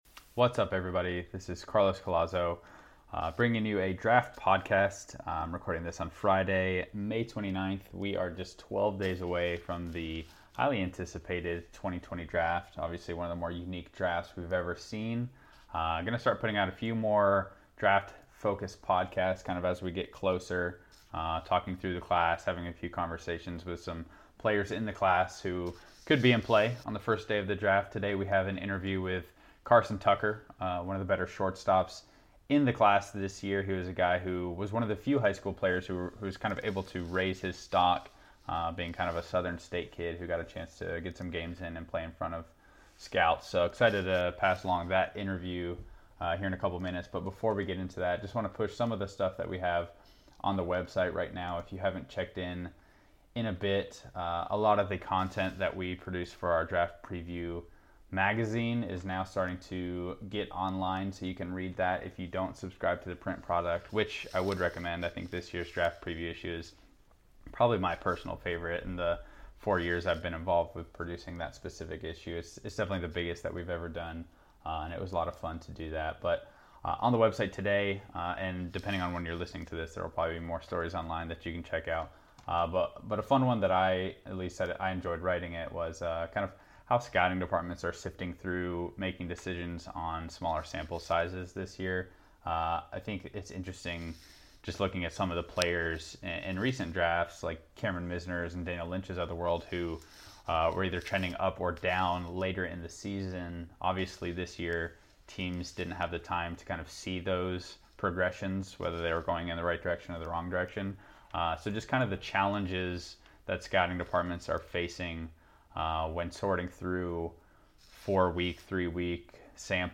Draft Podcast: A Conversation